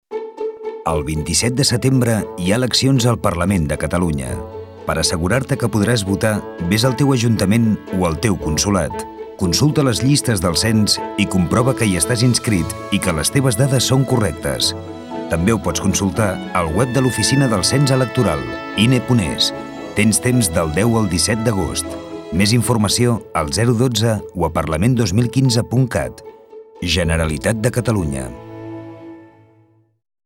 R�dio